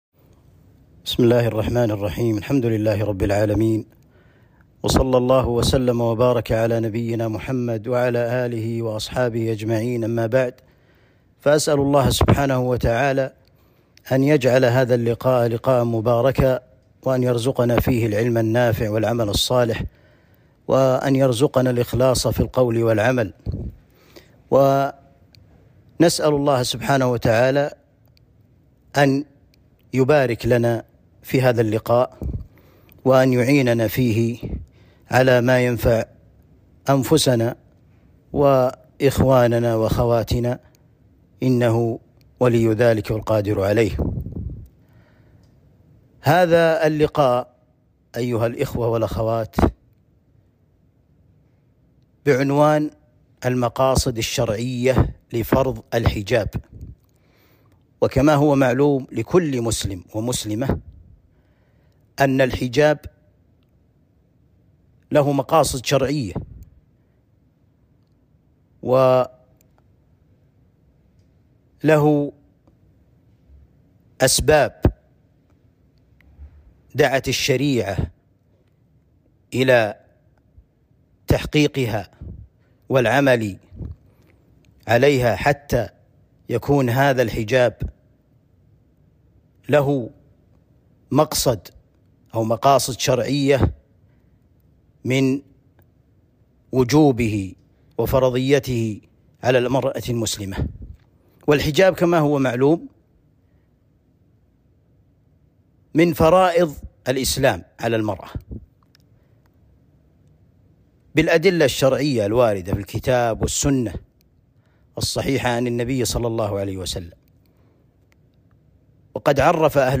لقاء
المحاضرات